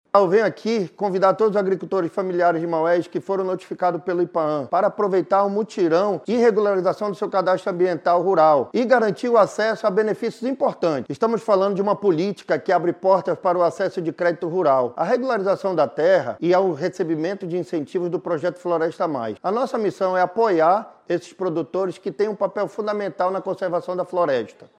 A ação faz parte do Projeto Floresta+ Amazônia e tem como objetivo auxiliar agricultores familiares e proprietários de pequenos imóveis rurais na atualização ou inscrição do CAR, instrumento essencial para a regularização ambiental e para o acesso a benefícios como o Pagamento por Serviços Ambientais (PSA), que oferece incentivos financeiros a quem conserva áreas com vegetação nativa, como destaca o diretor-presidente do Ipaam, Gustavo Picanço.